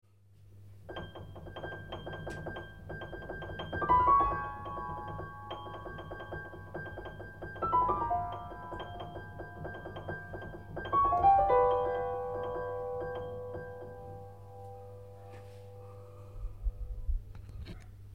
Je l'ai joué au piano: